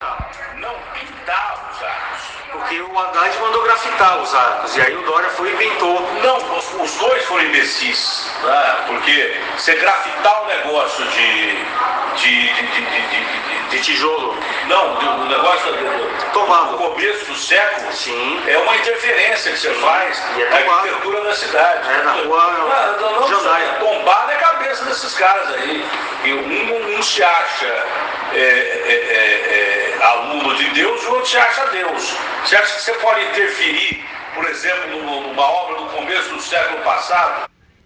A entrevista está gravada.